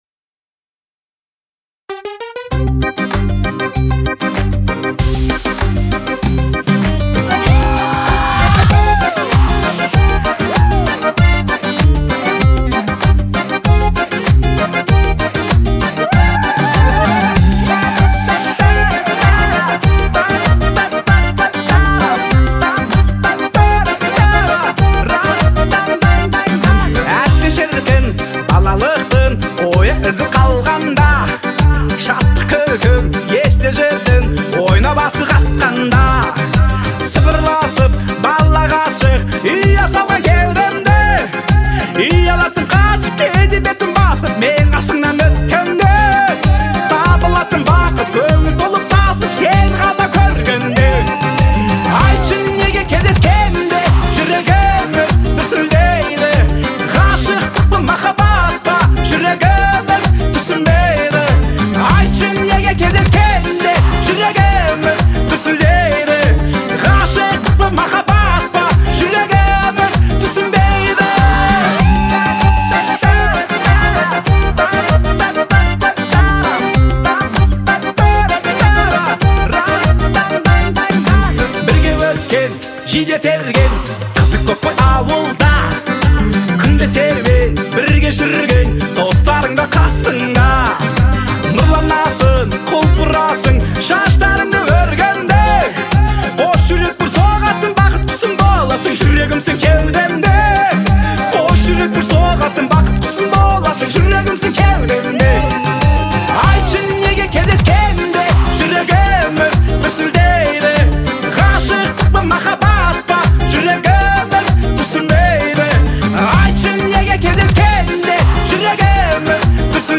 используя нежный вокал и гармоничное звучание инструментов.